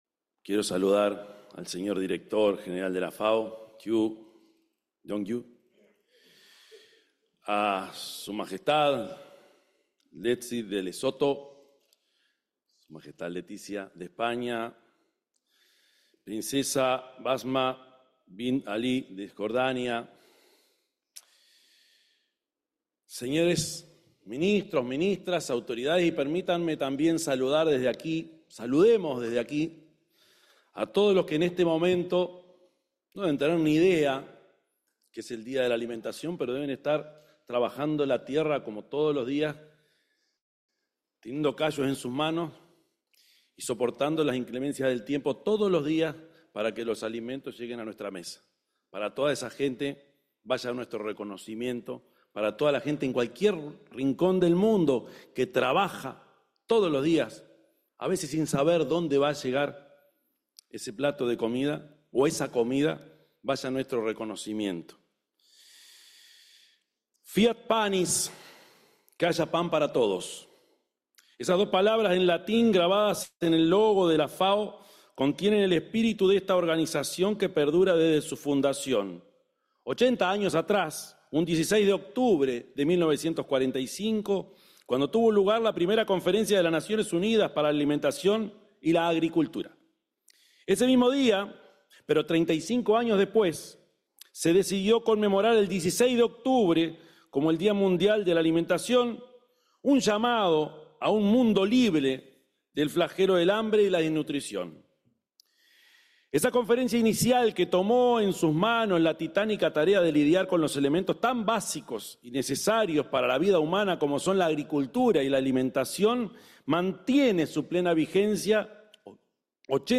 Palabras del presidente, Yamandú Orsi, en la FAO 16/10/2025 Compartir Facebook X Copiar enlace WhatsApp LinkedIn El presidente de la República, Yamandú Orsi, se expresó en la 45.ª celebración del Día Mundial de la Alimentación y el 80.° aniversario de la Organización de las Naciones Unidas para la Alimentación y la Agricultura (FAO) que se realizó en Roma, Italia.